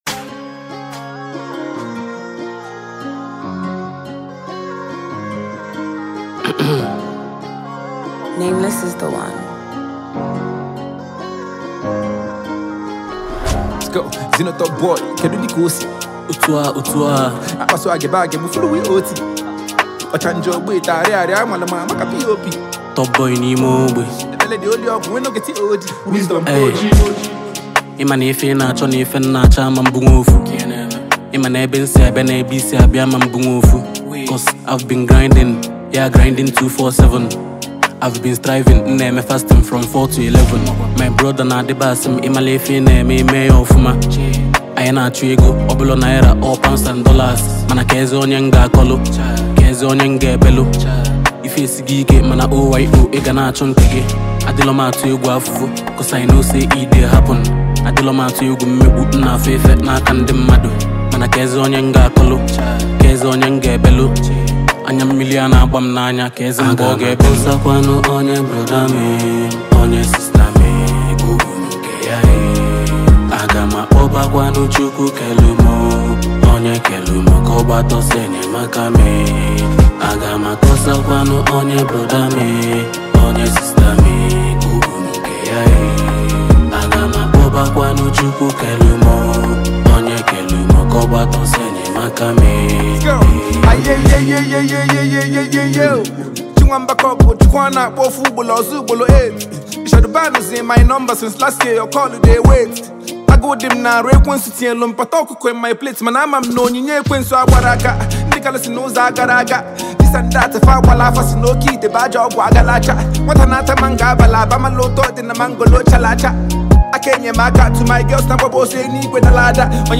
Highly talented fast rising Eastern Rapper
artist, songwriter, and rapper
velvety al soothing melody
sensational Nigerian Rap Heavyweight